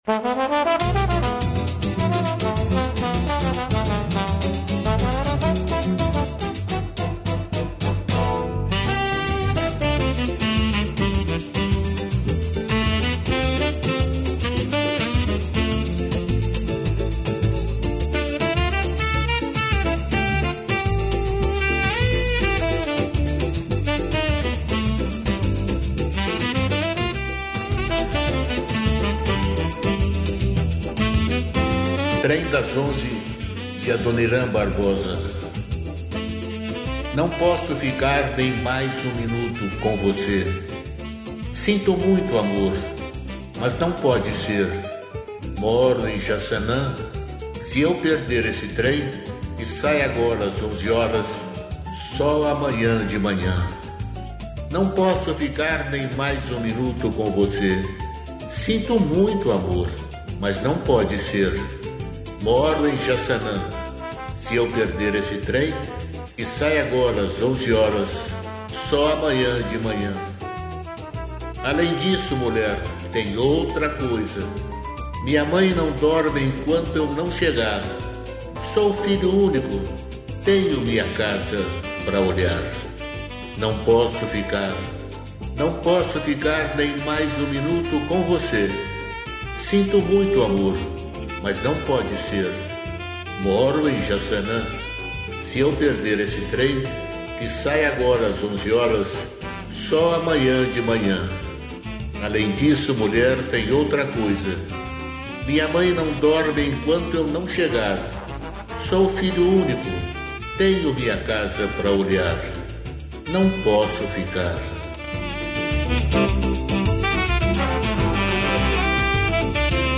música, arranjo: IA